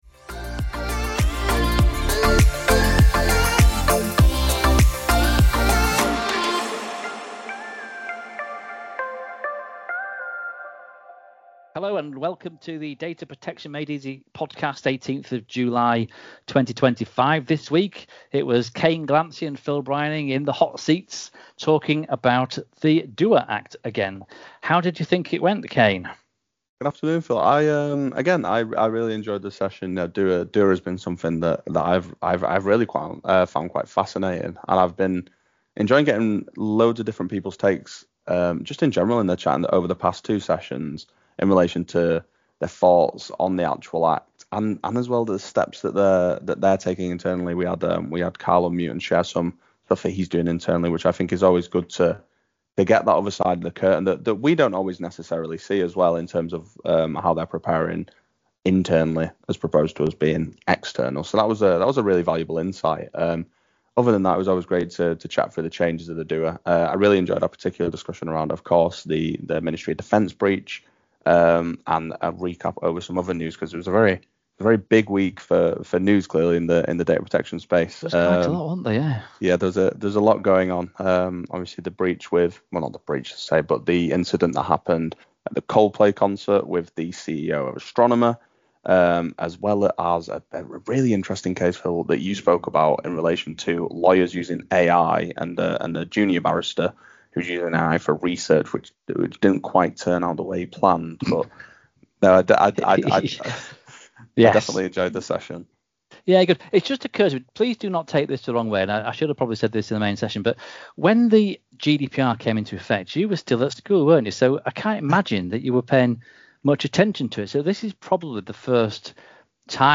With over 200 Data Protection Officers joining us live, this episode dives deeper into what’s changing, what’s not...